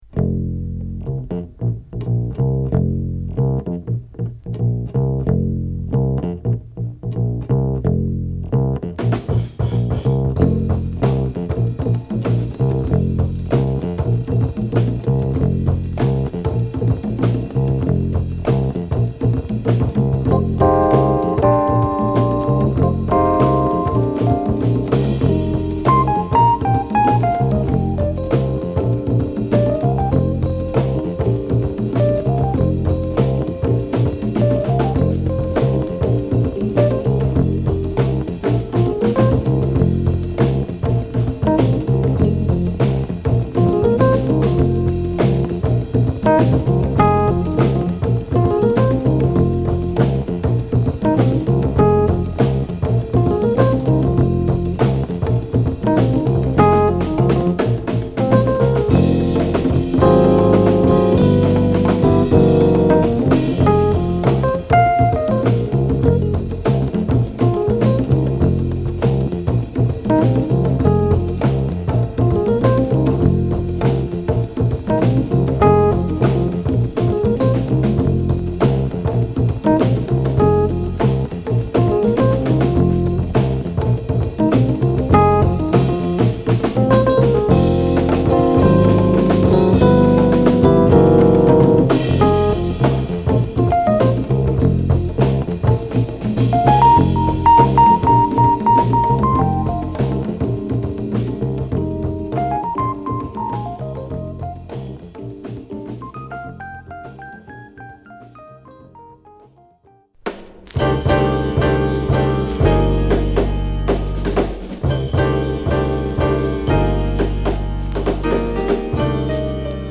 ＊B1頭に数回ノイズ有り。